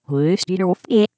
It doesn’t sound like it contains anything useful, but when we play it in reverse we hear